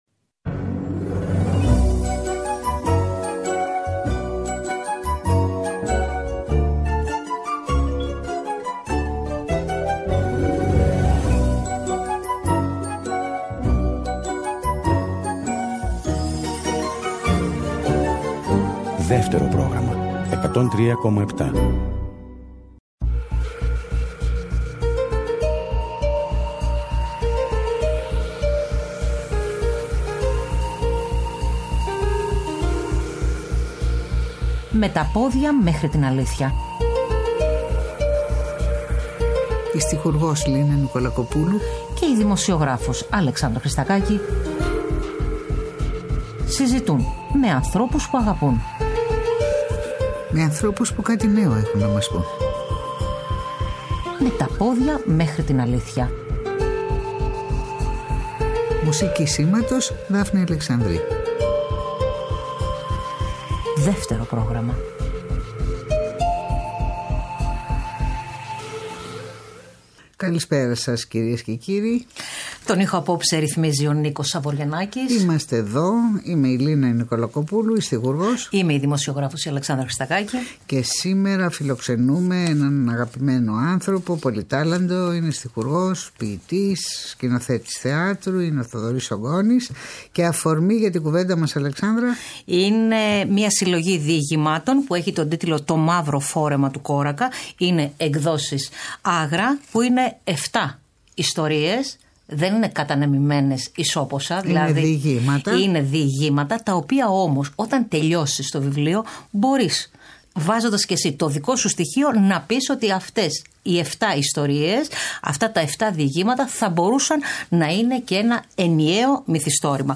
Ο στιχουργός, ποιητής και συγγραφέας Θοδωρής Γκόνης, καλεσμένος στις 10 Σεπτεμβρίου 2023 στην εκπομπή ” Με τα πόδια μέχρι την αλήθεια.”